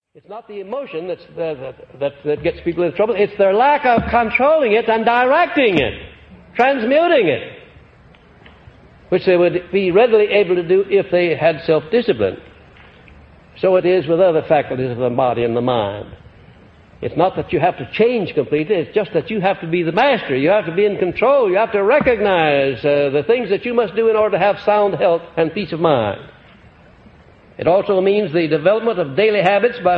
The lost recordings of Napoleon Hill are lectures as he personally promotes his philosophy of wealth and achievement; written about in his famous books Law of Success and Think and Grow Rich.